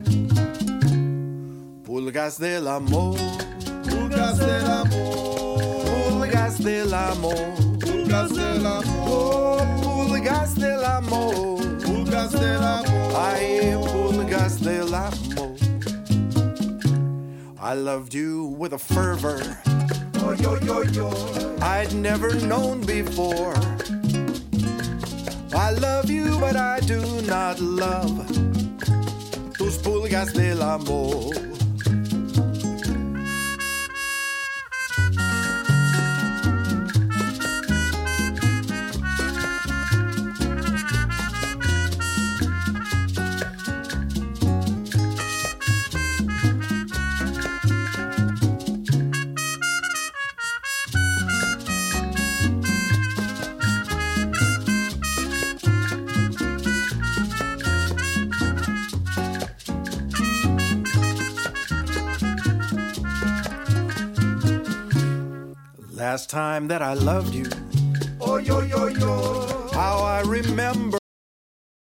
6 piece band
Great for dancing cumbia, meringue, twist.